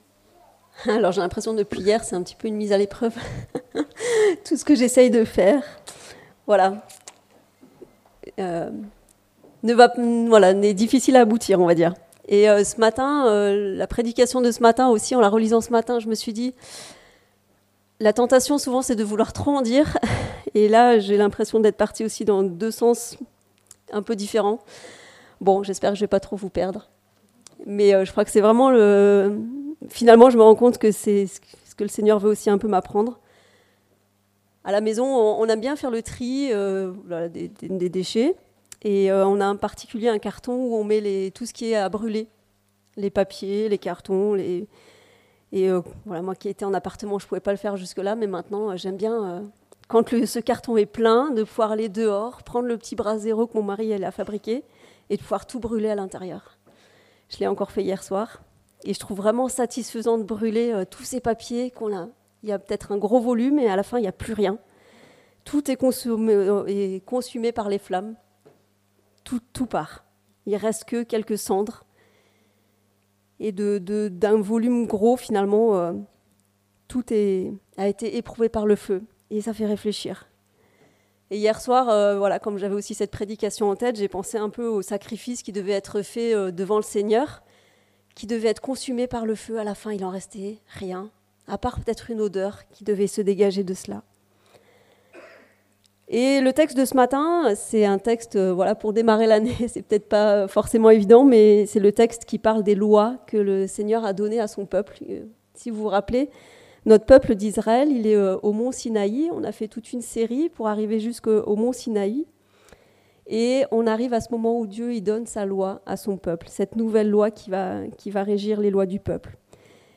Un peuple sain - Prédication de l'Eglise Protestante Evangélique de Crest sur le livre de l'Exode